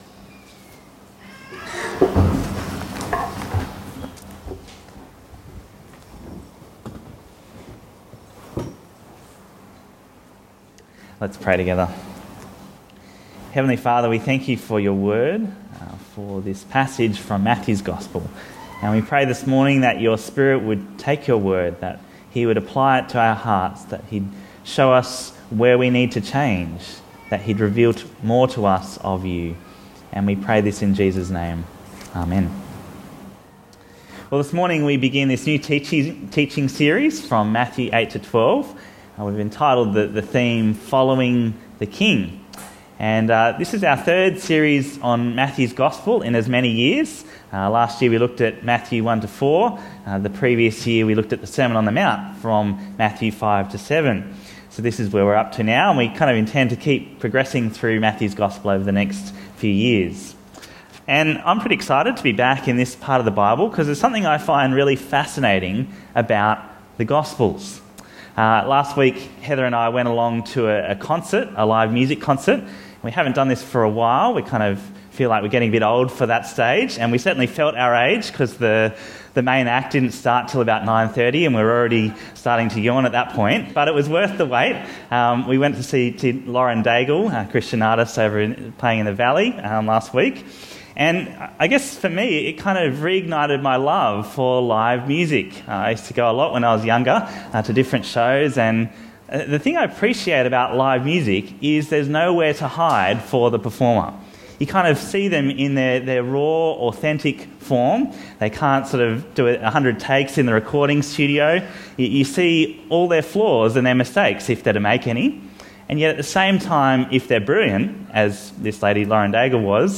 Bible Talks Bible Reading: Matthew 8:1-17